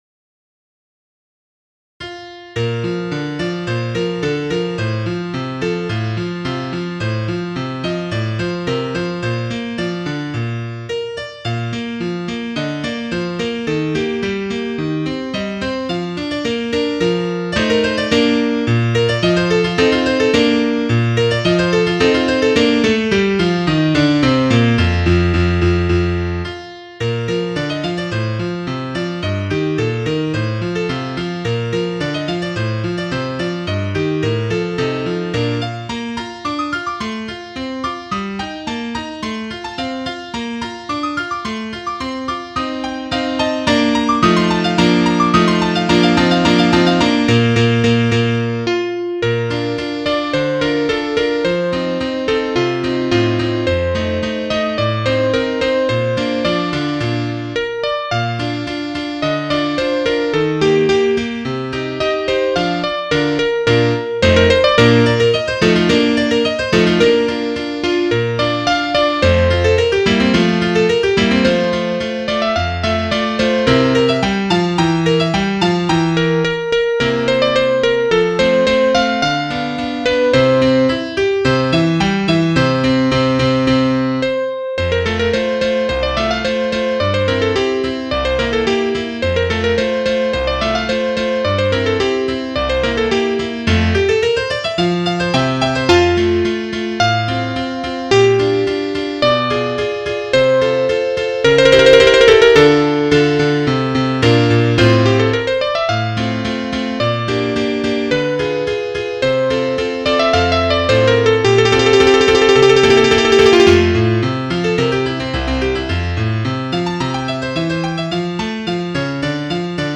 Bearbeitung in  B - Dur
für ein Soloinstrument mit Klavierbegleitung